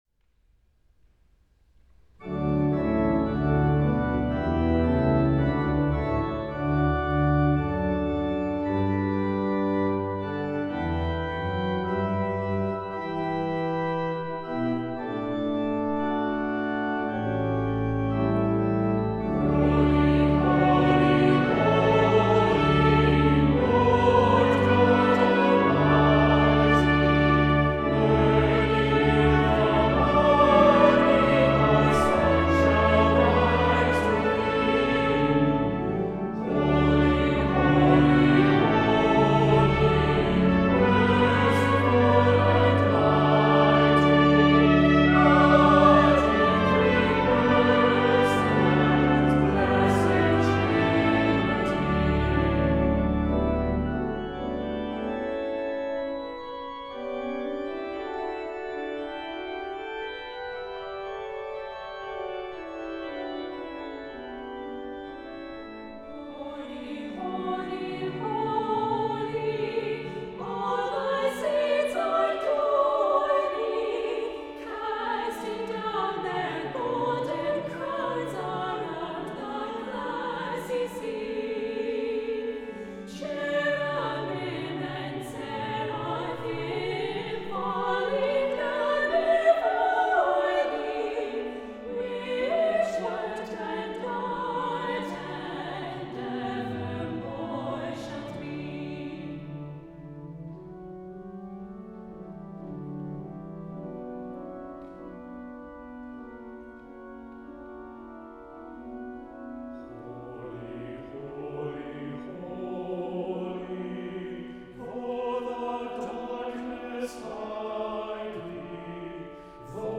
• Music Type: Choral
• Voicing: Congregation, SAB
• Accompaniment: Organ, Trumpet